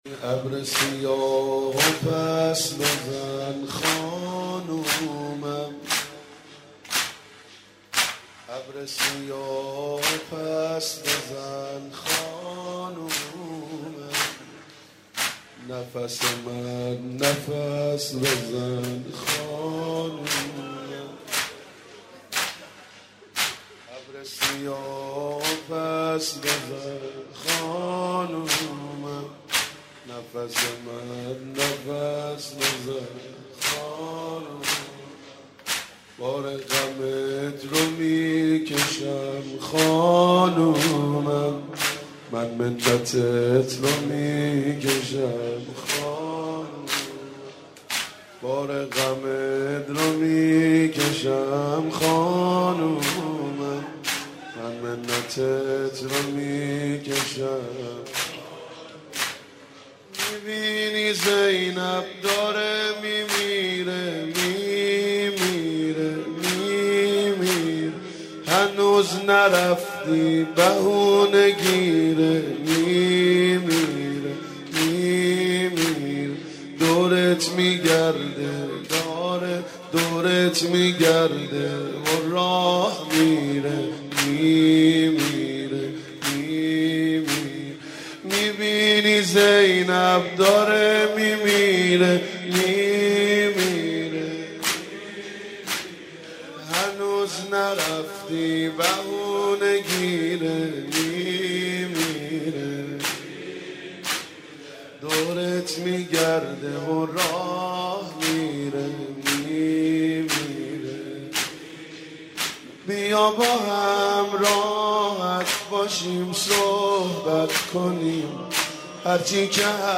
مداحی و نوحه
سینه زنی در شهادت مادر عاشورا، حضرت فاطمه زهرا(س